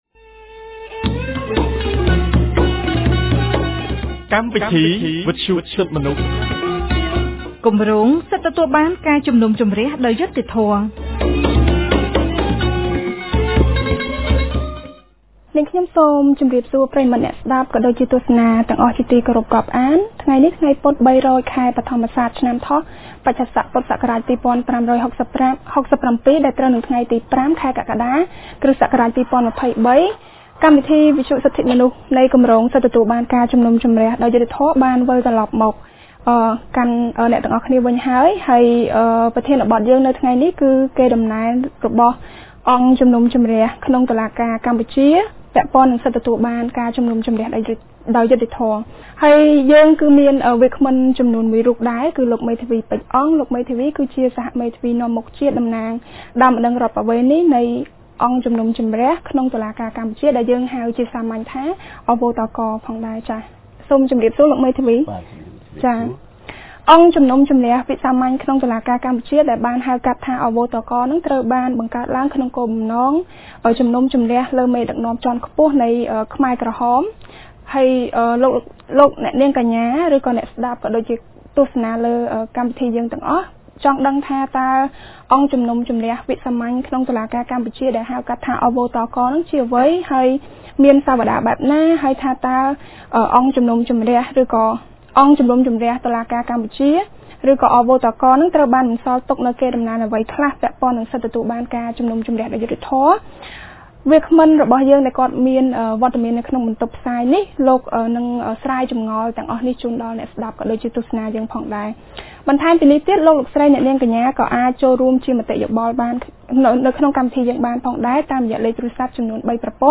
កាលពីថ្ងៃពុធ ទី៥ ខែកក្កដា ឆ្នាំ២០២៣ ចាប់ពីវេលាម៉ោង១១:០០ ដល់ម៉ោង ១២:០០ថ្ងៃត្រង់ គម្រាងសិទ្ធិទទួលបានការជំនុំជម្រះដោយយុត្តិធម៌នៃមជ្ឈមណ្ឌលសិទ្ធិមនុស្សកម្ពុជា បានរៀបចំកម្មវិធីវិទ្យុក្រោមប្រធានបទស្តីពី កេរដំណែលរបស់ អ.វ.ត.ក ពាក់ព័ន្ធនឹងសិទ្ធិទទួលបានការជំនុំជម្រះដោយយុត្តិធម៌